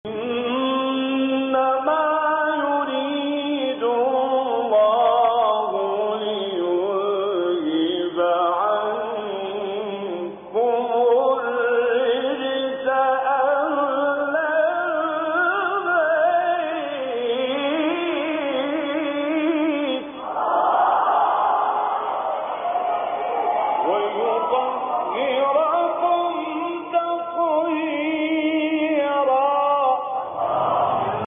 به بهانه سالروز تخریب بقاع متبرکه بقیع کرسی تلاوت مجازی با محوریت آیه تطهیر را با صدای راغب مصطفی غلوش، کامل یوسف البهتیمی، شعبان عبدالعزیز صیاد و سیدمتولی عبدالعال از قاریان شهیر جهان اسلام می‌شنوید.
تلاوت آیه تطهیر با صوت راغب مصطفی غلوش